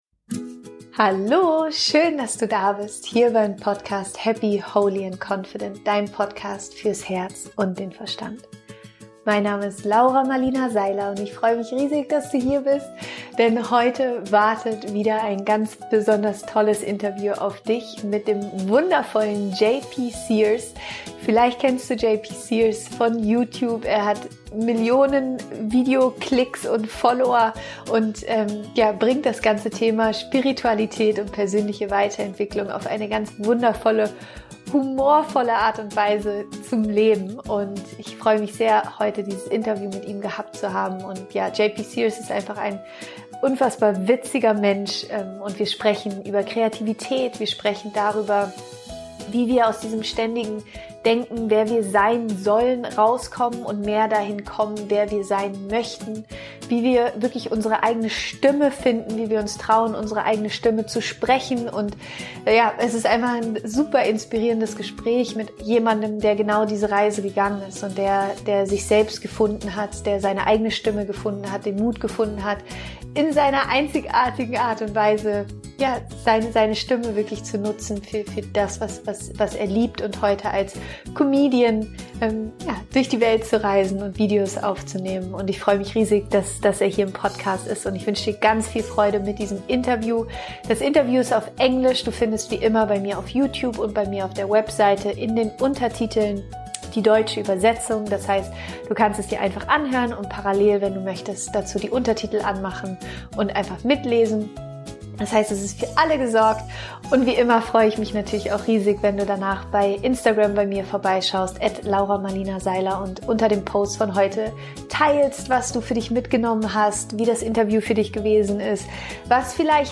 Ich freu mich riesig heute ein ganz wundervolles Interview mit dir zu teilen.
Warum es so wichtig ist, dass du deine Stimme findest und nutzt - Interview mit JP Sears